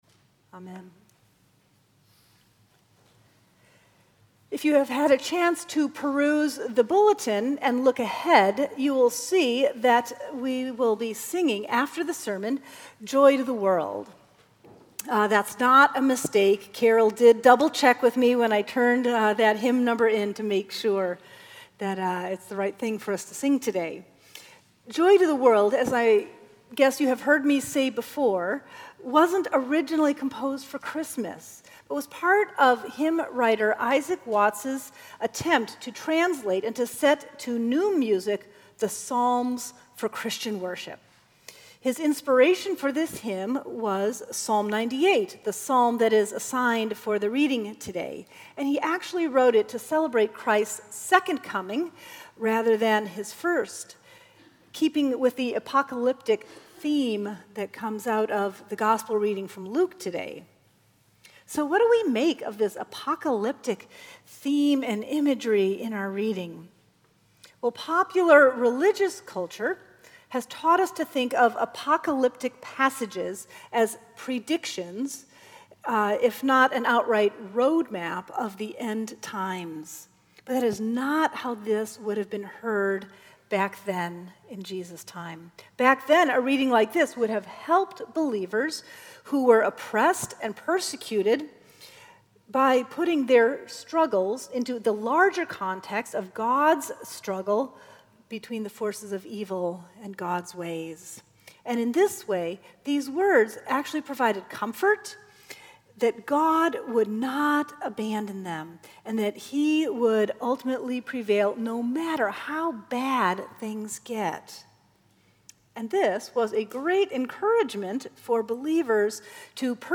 Sermons at Union Congregational Church
November 13, 2016 Pledge Sunday Twenty-sixth Sunday after Pentecost